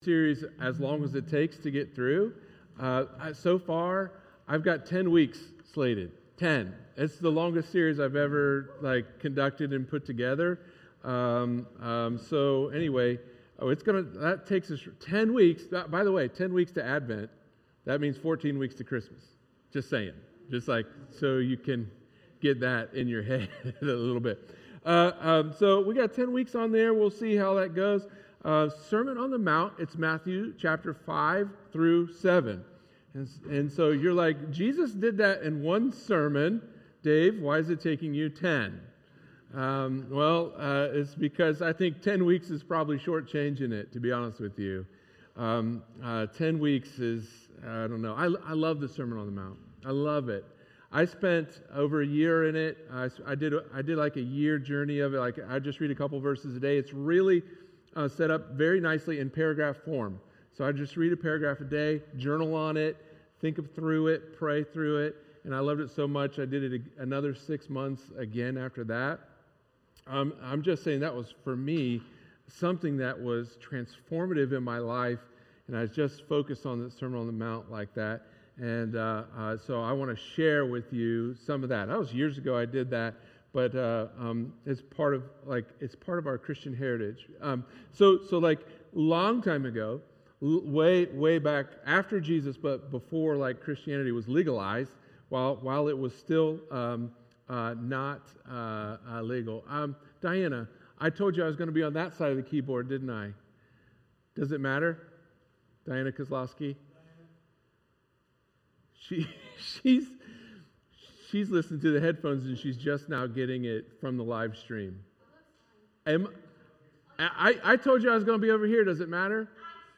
sermon-on-the-mount-introduction.mp3